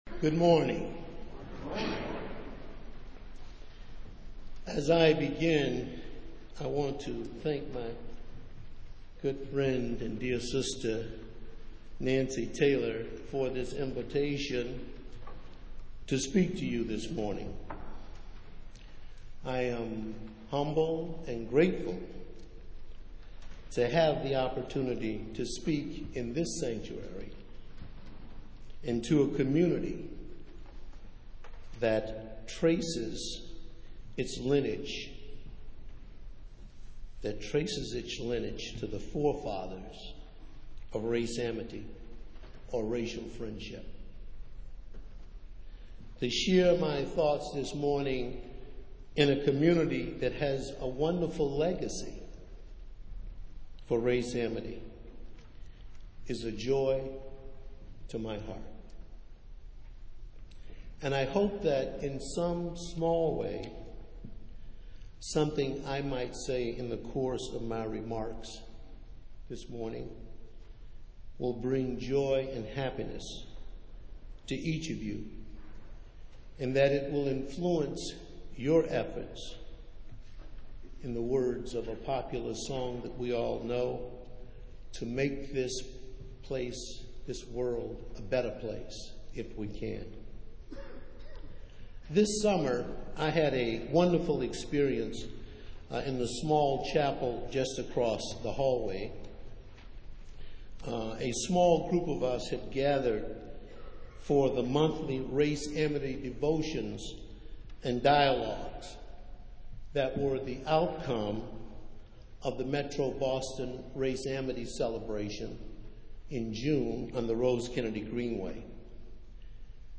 Festival Worship - Martin Luther King, Jr. Sunday